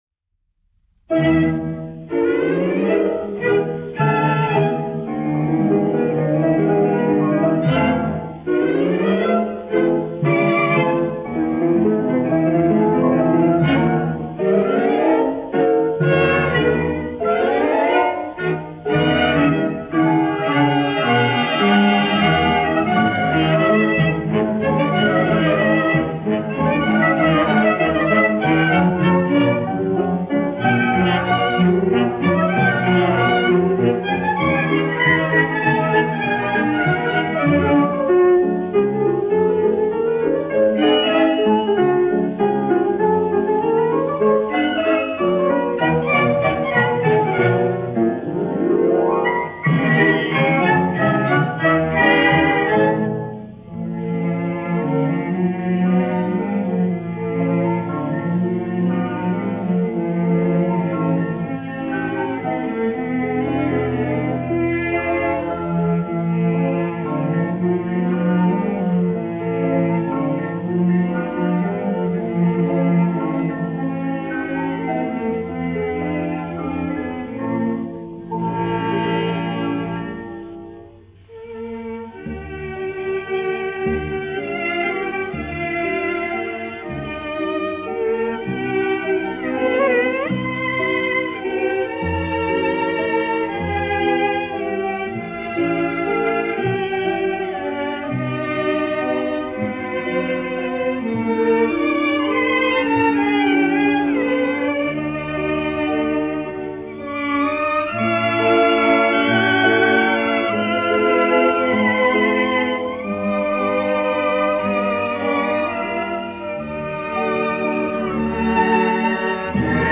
1 skpl. : analogs, 78 apgr/min, mono ; 25 cm
Orķestra mūzika, aranžējumi
Popuriji
20. gs. 30. gadu oriģinālās skaņuplates pārizdevums ASV
Skaņuplate